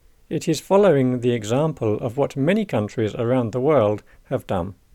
DICTATION 6